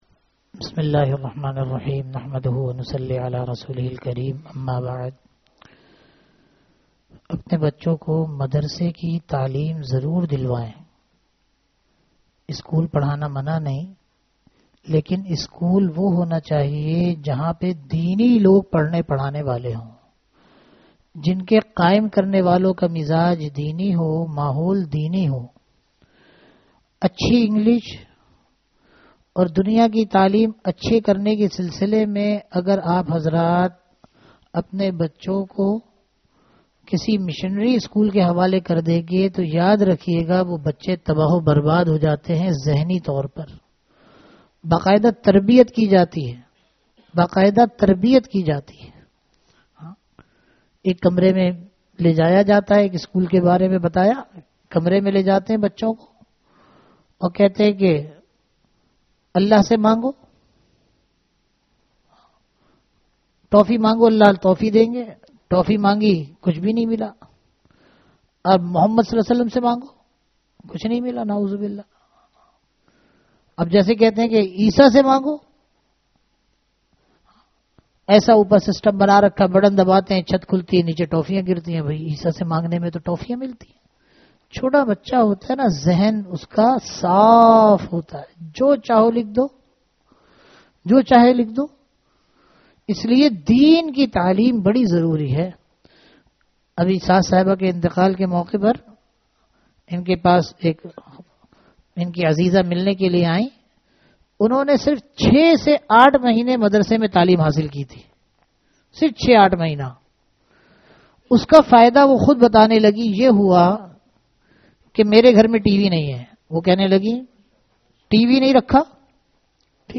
Bayanat